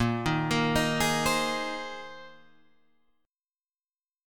A#7sus2sus4 chord